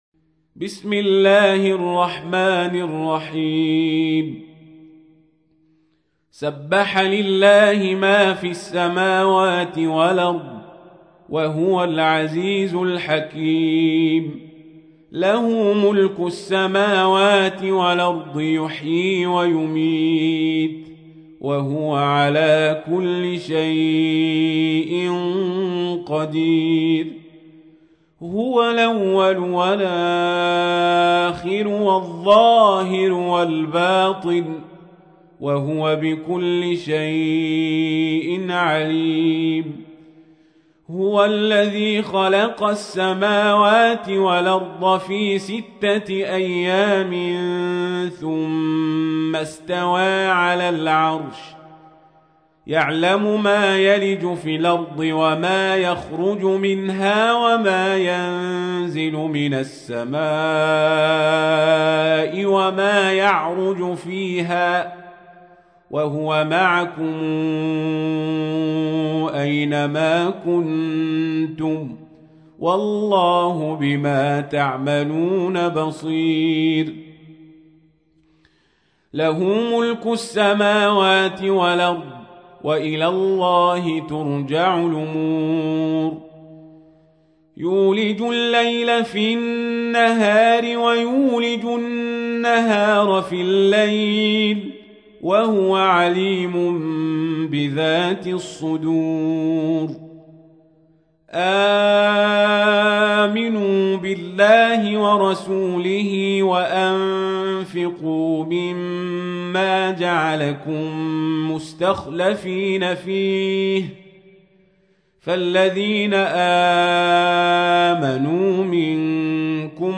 تحميل : 57. سورة الحديد / القارئ القزابري / القرآن الكريم / موقع يا حسين